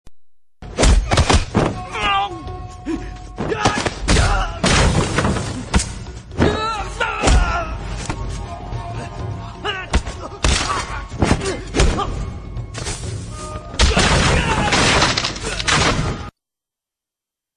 Âm thanh tiếng Đánh Nhau trong phim